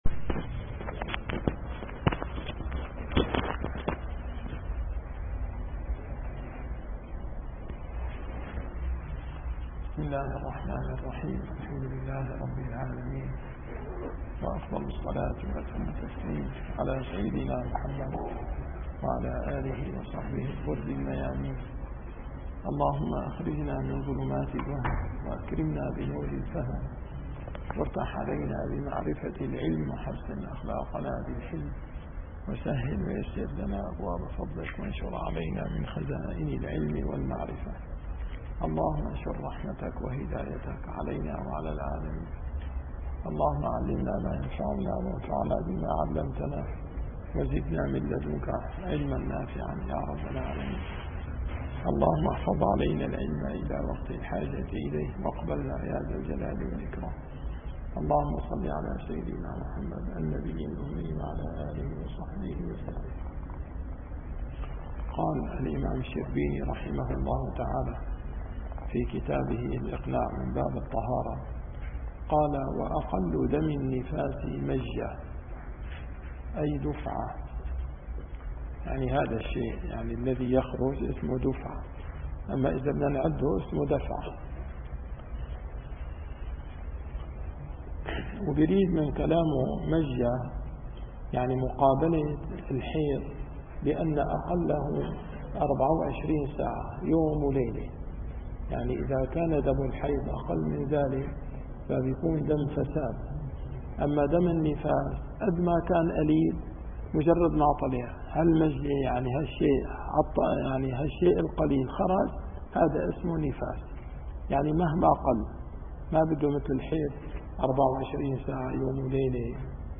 - الدروس العلمية - الفقه الشافعي - كتاب الإقناع - أقل الحيض وأكثره